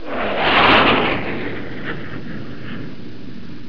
دانلود آهنگ طیاره 51 از افکت صوتی حمل و نقل
جلوه های صوتی
دانلود صدای طیاره 51 از ساعد نیوز با لینک مستقیم و کیفیت بالا